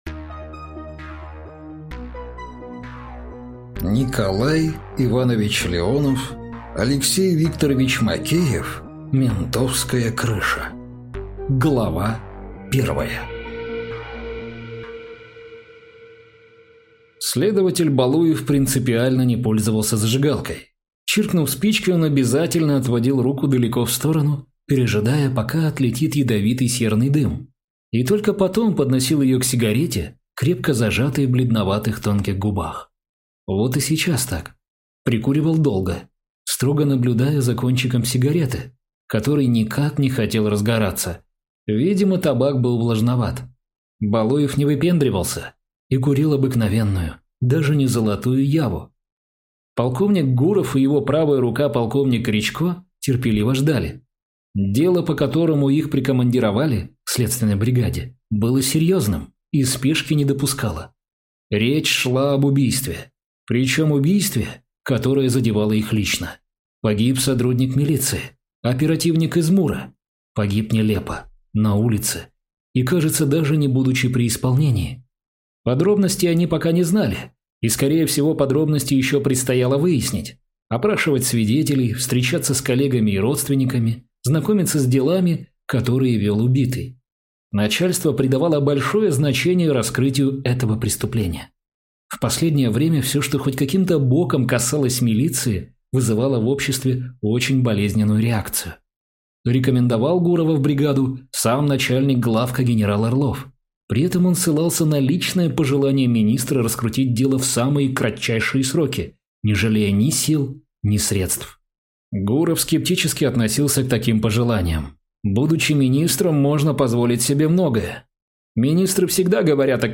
Аудиокнига Ментовская крыша | Библиотека аудиокниг